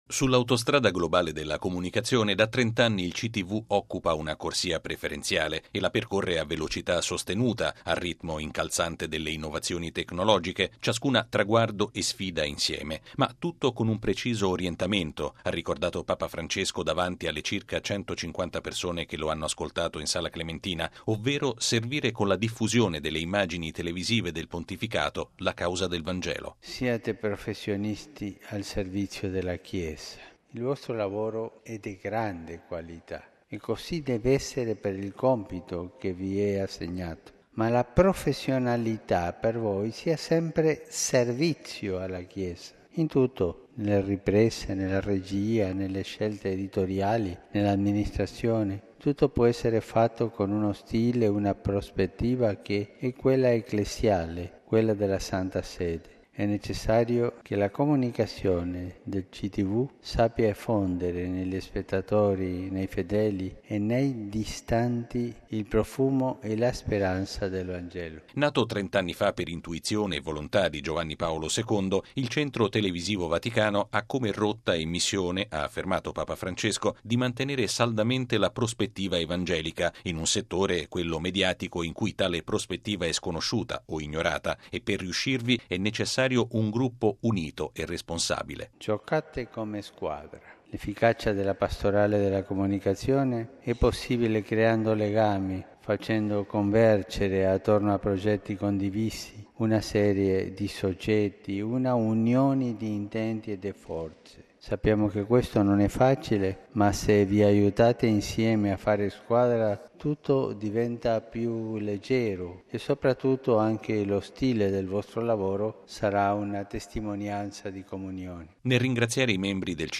Ma tutto con un preciso orientamento, ha ricordato Papa Francesco davanti alle circa 150 persone che lo hanno ascoltato in Sala Clementina, ovvero servire con la diffusione delle immagini televisive del Pontificato la causa del Vangelo: